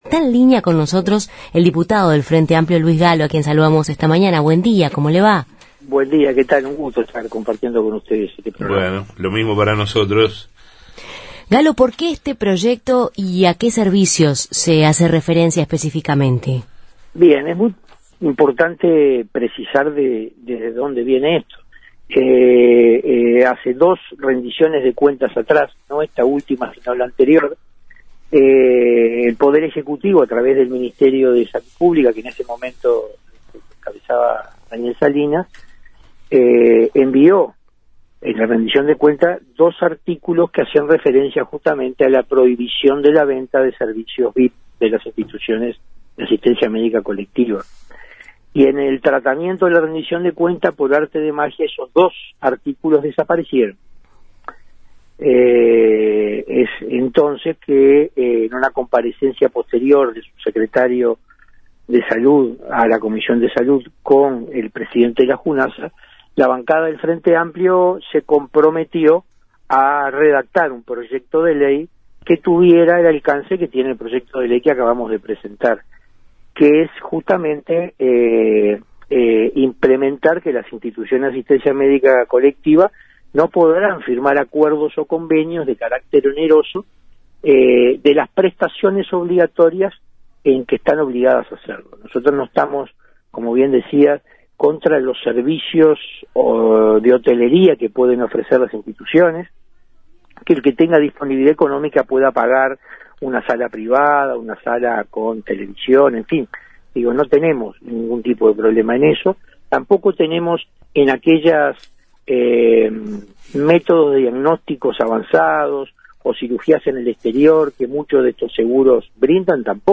En Justos y pecadores entrevistamos al diputado frenteamplista doctor Luis Gallo sobre el proyecto contra los servicios VIP de mutualistas